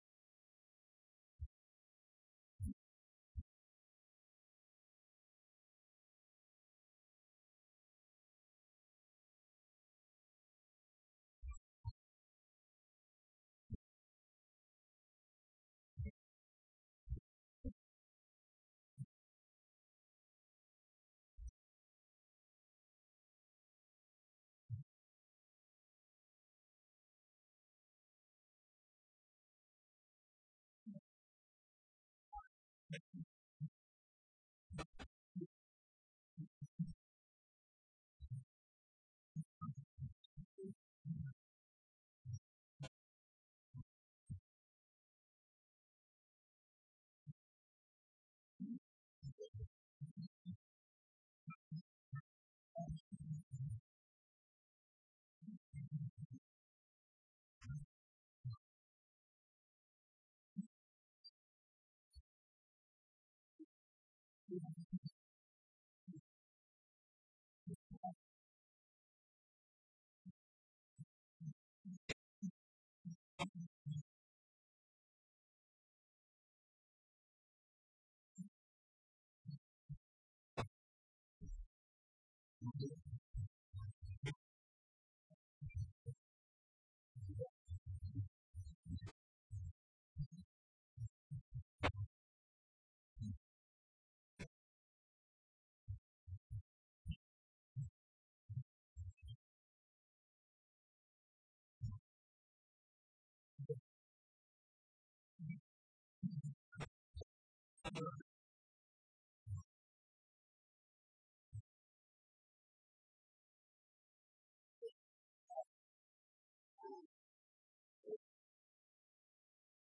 Rádio Debate pega fogo no encontro entre os vereadores Ronaldo Pacas e Carlinhos da Cohab
Em mais um Rádio Debate, os vereadores Ronaldo Pacas (PSDC) e Carlinhos da Cohab (PSL) falaram sobre os assuntos que foram destaque na pauta política santa-cruzense.
O fato gerou um debate quente entre os vereadores.